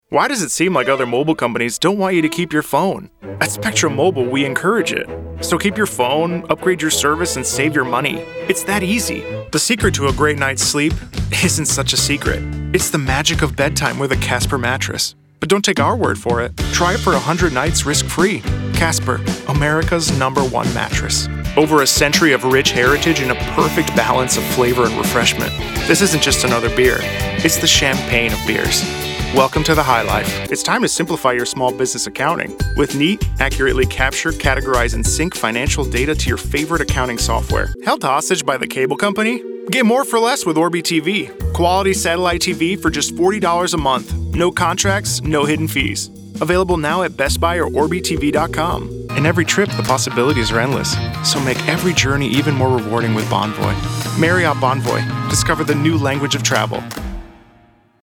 englisch (us)
Sprechprobe: Werbung (Muttersprache):
I am a former opera singer turned voice actor.
Commercials